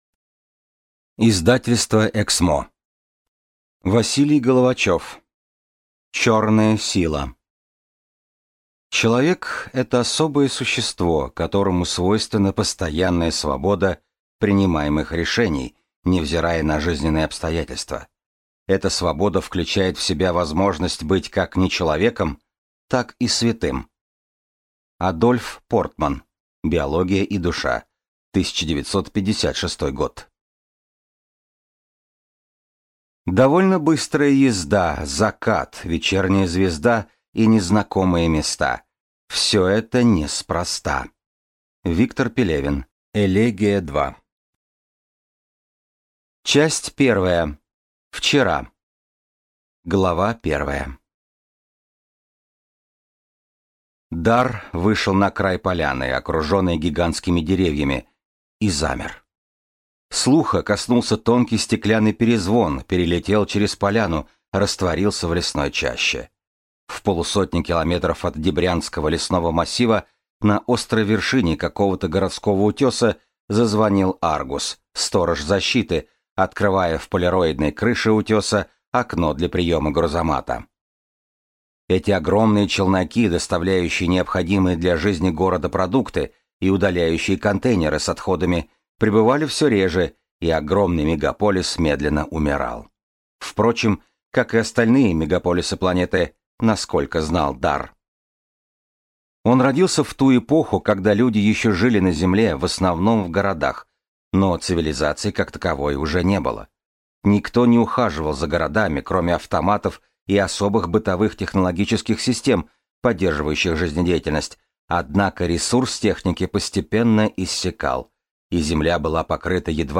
Аудиокнига Черная сила | Библиотека аудиокниг
Прослушать и бесплатно скачать фрагмент аудиокниги